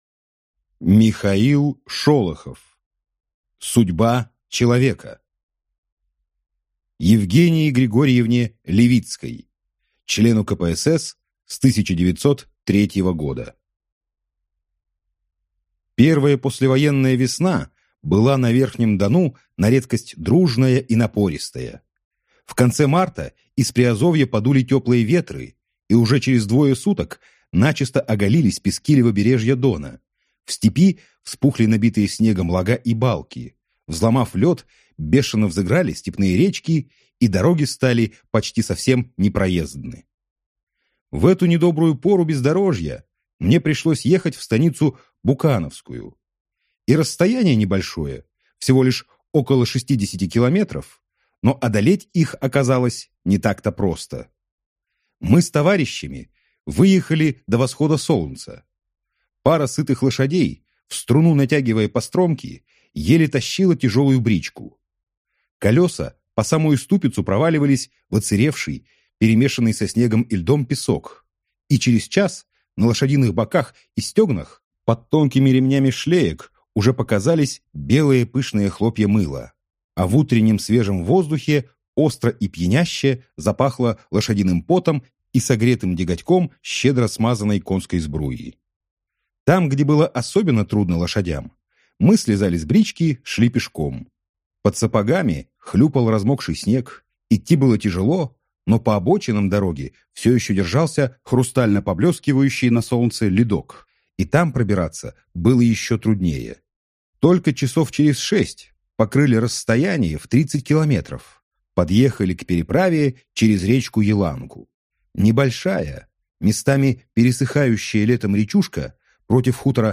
Аудиокнига Судьба человека | Библиотека аудиокниг